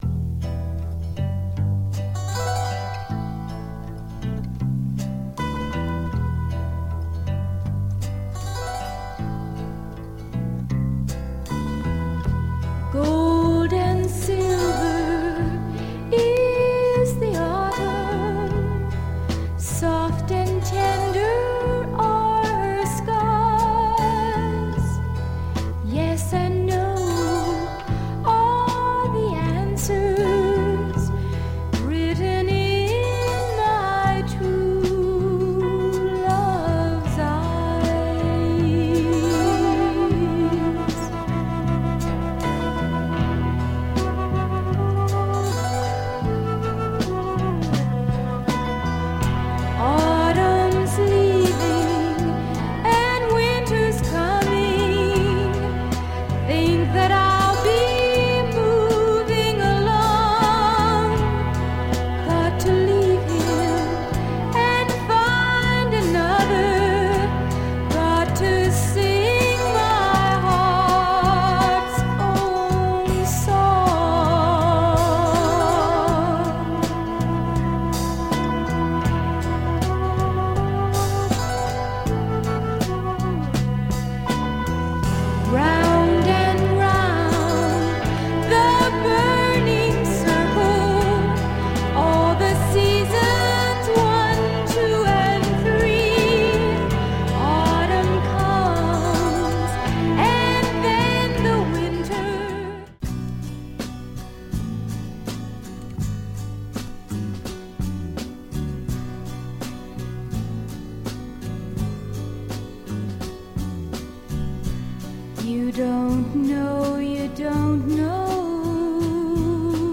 Some magnificent folk soul tunes
Canadian female singer
No sitar this time, but a harpsichord !